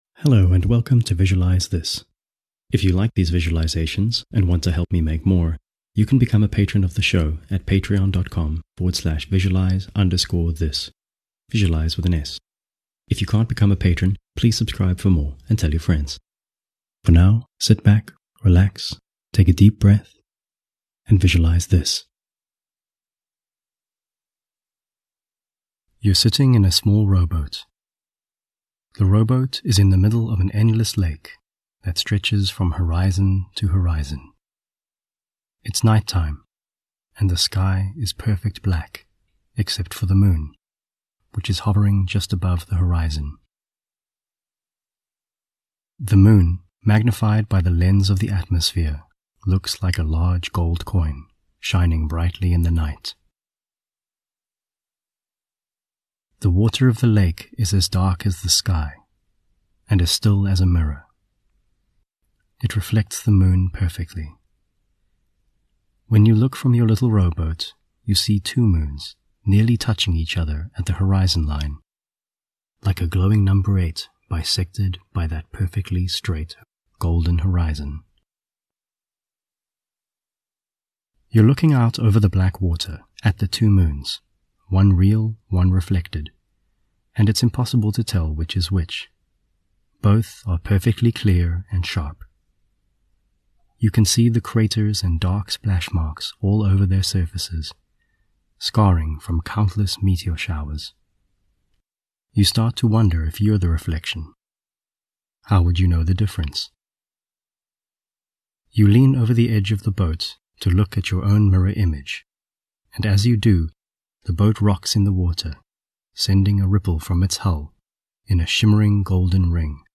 This is a calm, imaginative, slightly magical visualisation that will take you to a small rowboat in the middle of an endless lake, where you can watch the night sky.